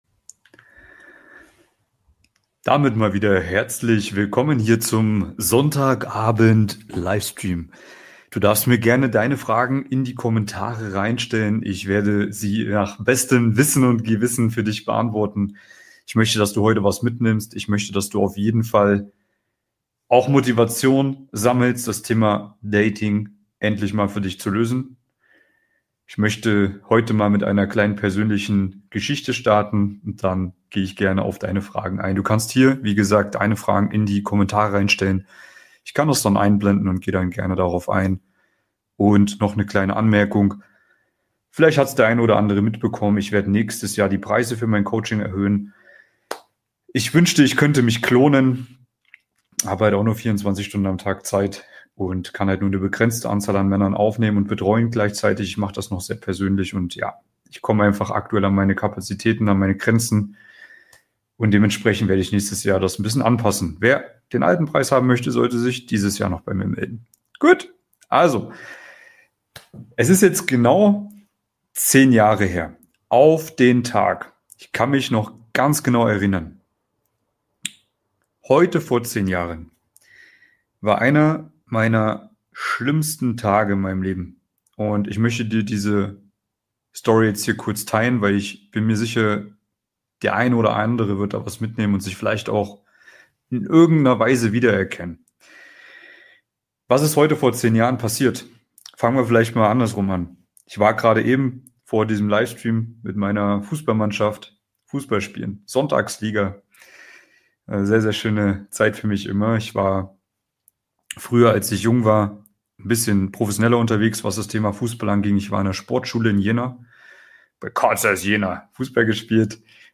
Live Q&A: Frauen verstehen, verführen und behalten: Mission Traumfrau 2026 ~ Mission Traumfrau – Für Männer mit Anspruch Podcast
Am Sonntag, den 26. Oktober um 20:15 Uhr, gehe ich live und beantworte deine Fragen rund ums Thema Dating, Flirten und Partnersuche.